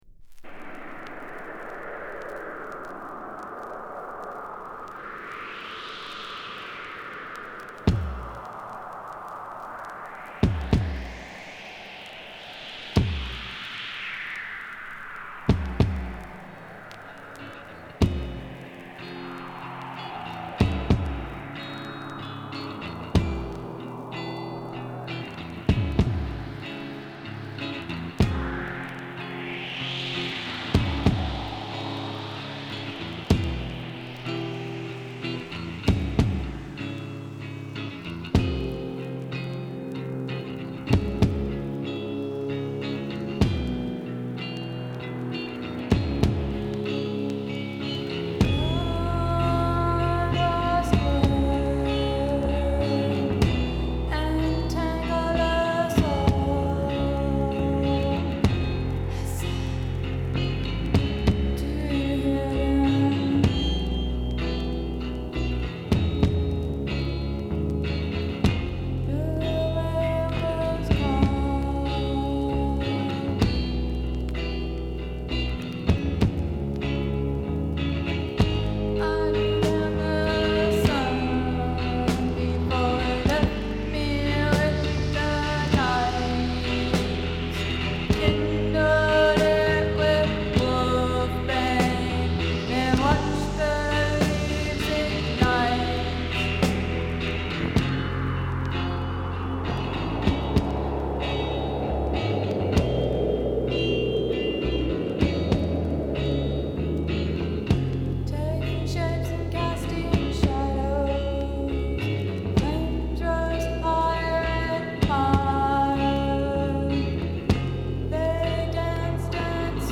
息が詰まりそうな陰鬱かつトライバルなポストパンクを叩きつける。呪術的な女性ヴォーカルが印象的だ。